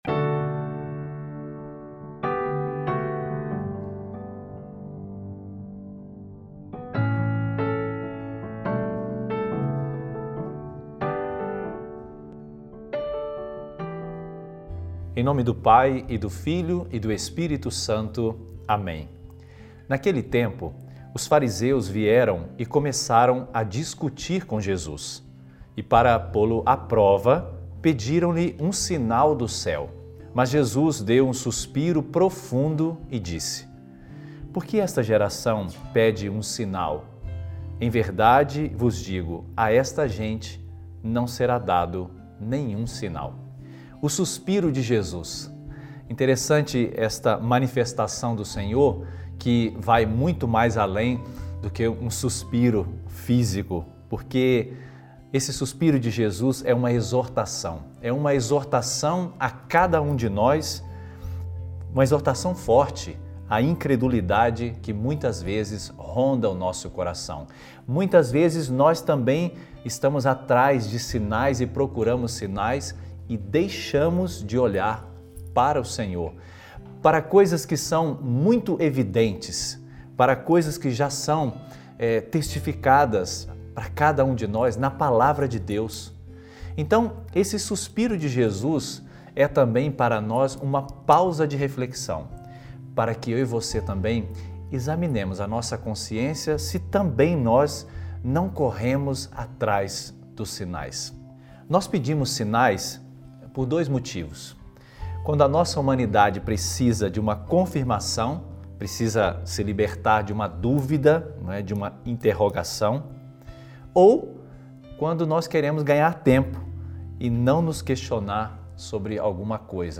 Reflexão sobre o Evangelho